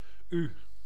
Ääntäminen
IPA: [y]